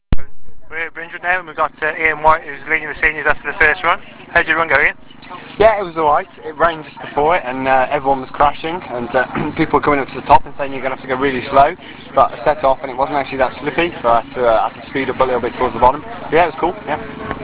Interviews.
We have two interviews just after their first runs of the morning.